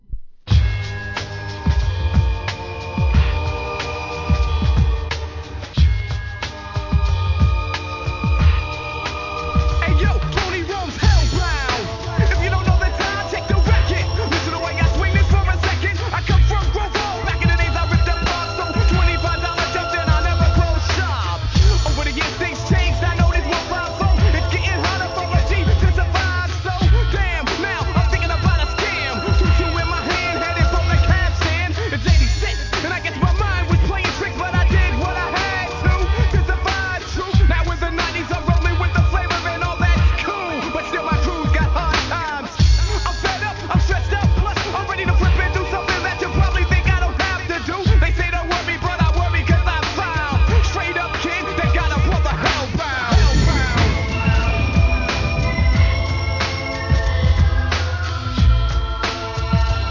HIP HOP/R&B
使いのハーコーサウンド！！